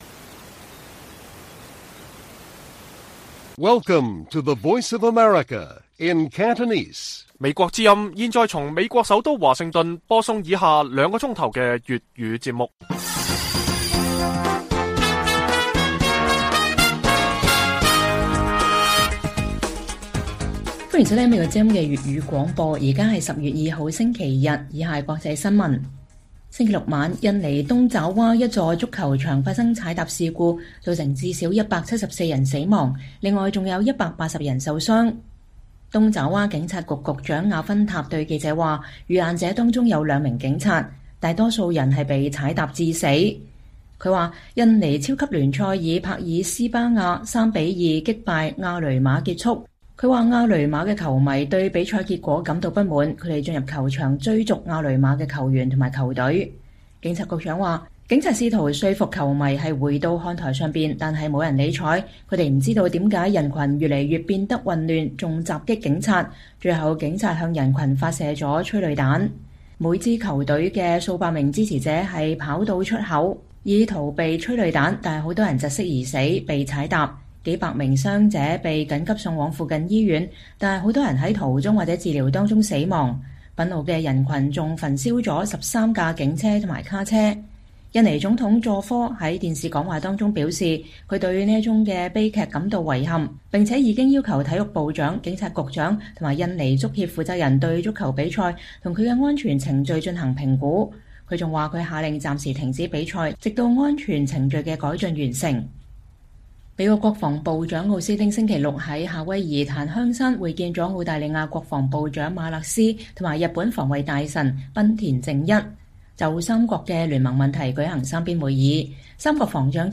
粵語新聞 晚上9-10點：印尼足球比賽結束後發生騷亂 至少174人死亡